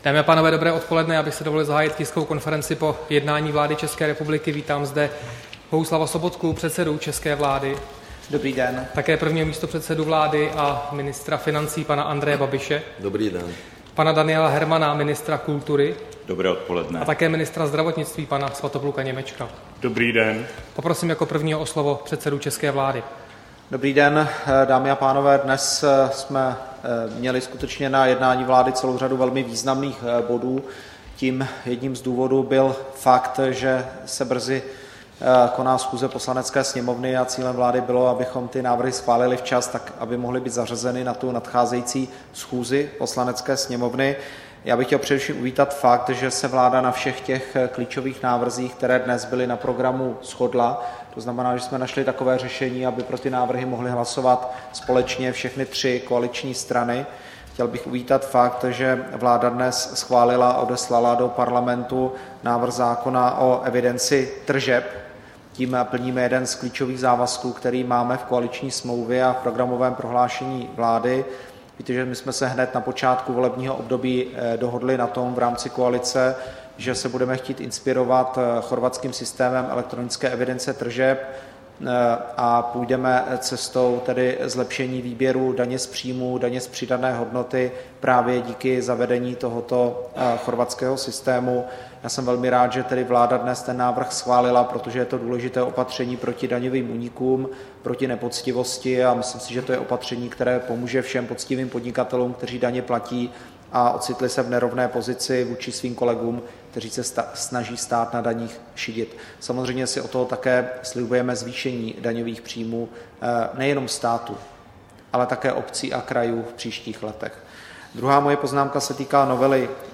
Tisková konference po jednání vlády, 3. června 2015